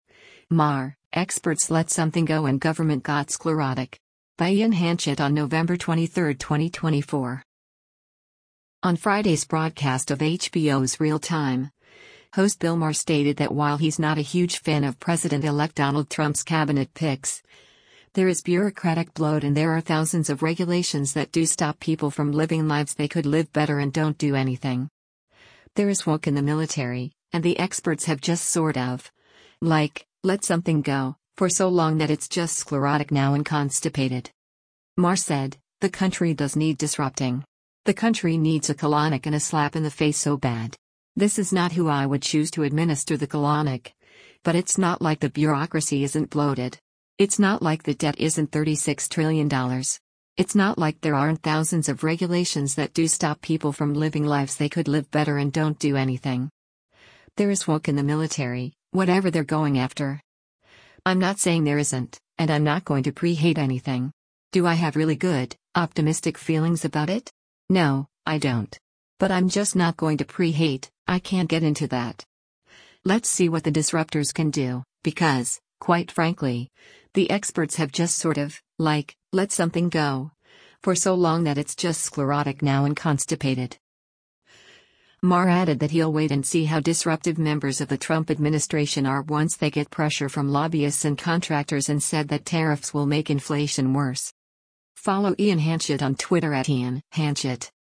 On Friday’s broadcast of HBO’s “Real Time,” host Bill Maher stated that while he’s not a huge fan of President-Elect Donald Trump’s Cabinet picks, there is bureaucratic bloat and there are “thousands of regulations that do stop people from living lives they could live better and don’t do anything. There is woke in the military,” and “the experts have just sort of, like, let something go, for so long that it’s just sclerotic now and constipated.”